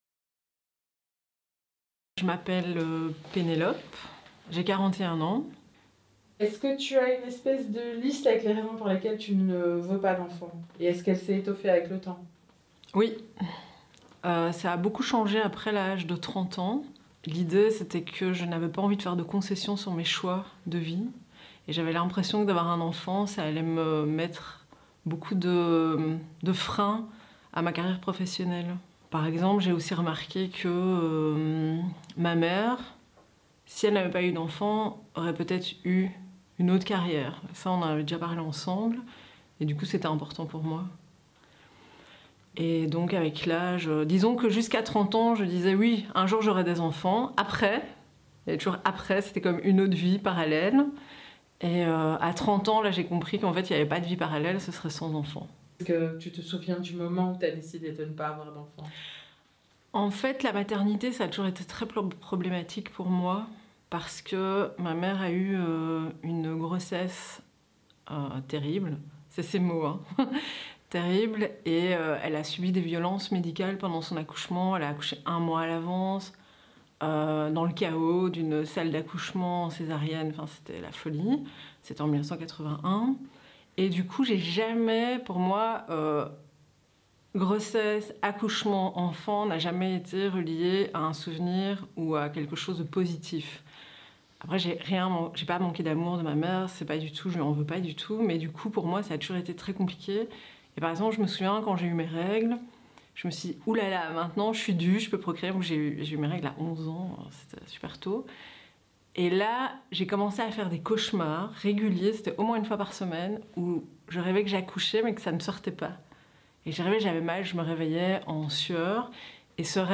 extrait du témoignage